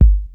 Kick Syn 03 X5.wav